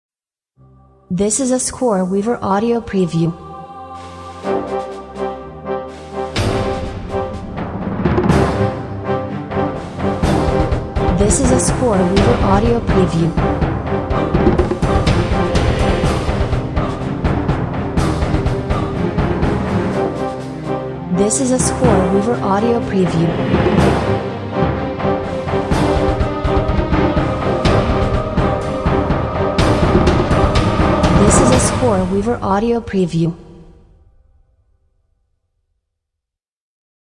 Fast paced and highly dramatic orchestral music!